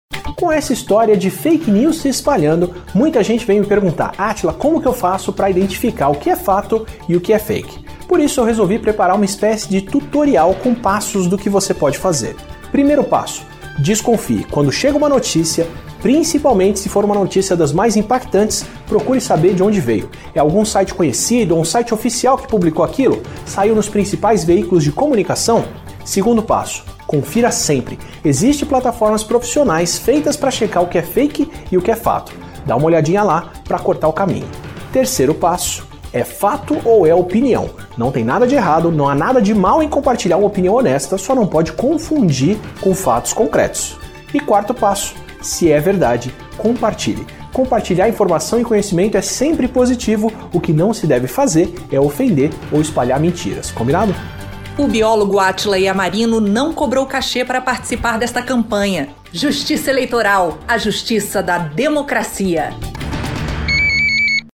3-spot-passo-a-passo.mp3